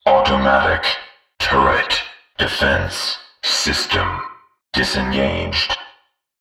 voxTurretOff.ogg